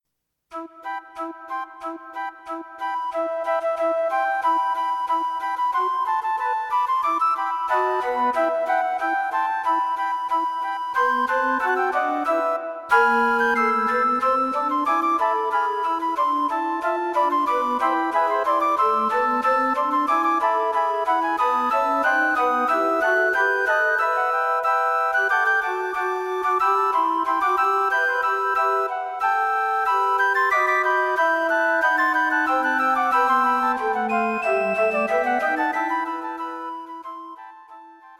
Arranged for - Piccolo, Flute 1, Flute 2, Alto Flute.